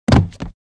collapse1.wav